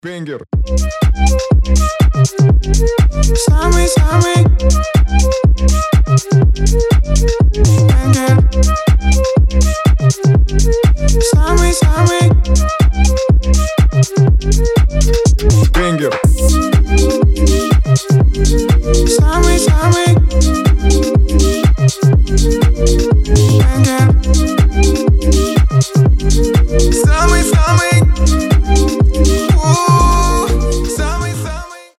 • Качество: 320, Stereo
позитивные
мужской голос
легкие